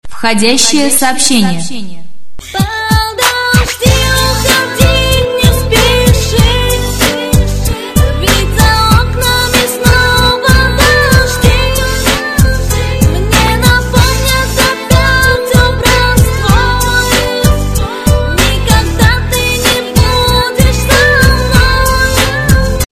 » Входящ сообщение с музыкой Размер: 317 кб